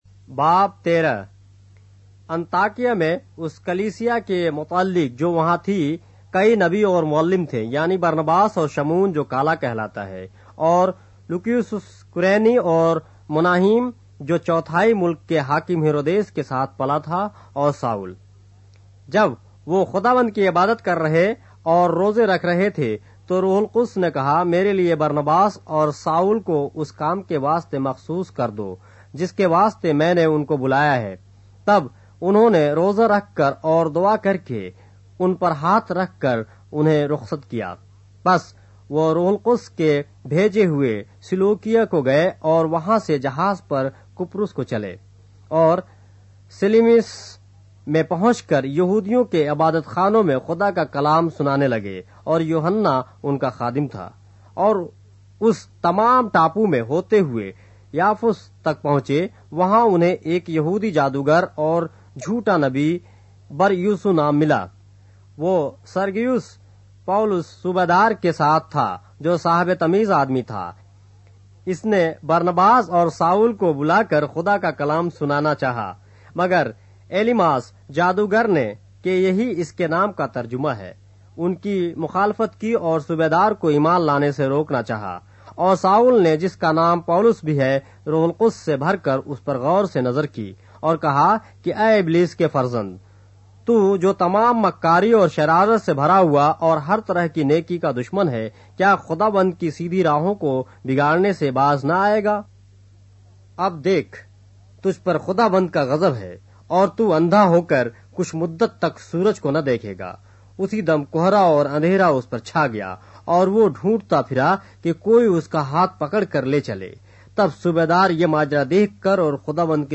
اردو بائبل کے باب - آڈیو روایت کے ساتھ - Acts, chapter 13 of the Holy Bible in Urdu